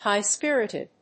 /ˌhaɪˈspɪrɪdɪd(米国英語)/
アクセントhígh‐spírited
high-spirited.mp3